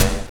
SI2 KLACK.wav